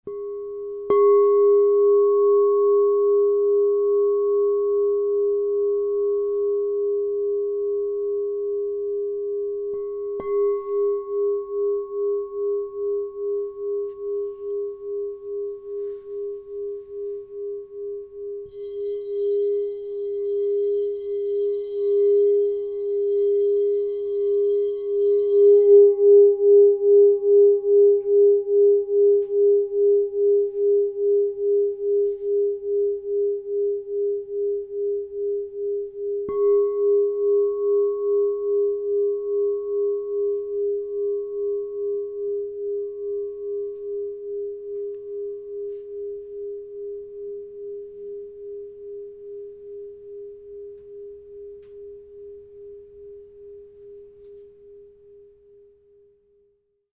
Tesseract Salt, Aqua Aura Gold 6″ G# -35 Crystal Tones Singing Bowl
Its compact form carries a crystalline voice—bright, focused, and deeply transformative—perfect for personal meditation, ceremonial use, or as a complement in harmonic sets.
432Hz (-)
G#